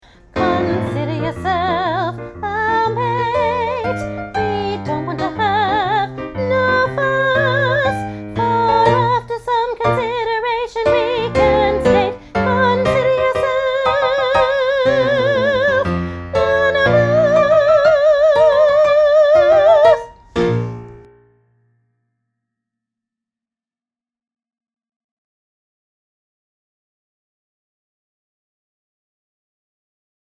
Junior Choir – Oliver, Consider Yourself (finale), Alto